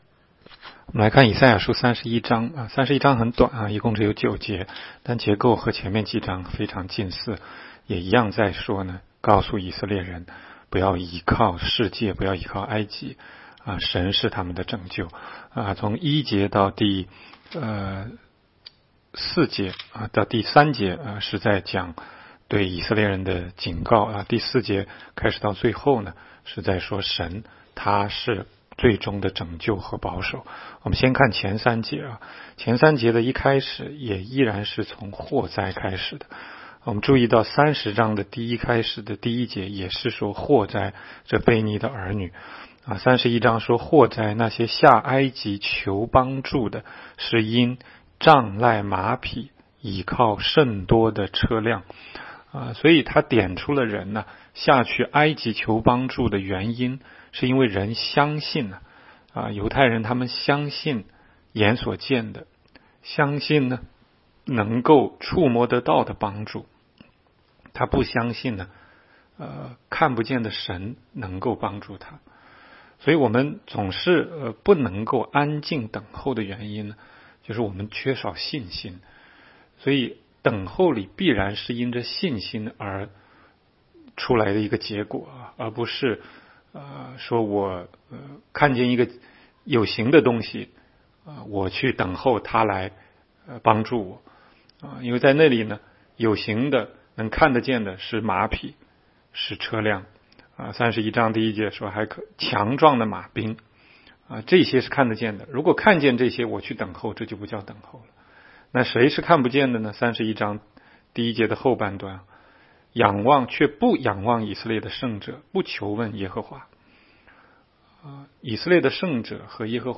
16街讲道录音 - 每日读经 -《 以赛亚书》31章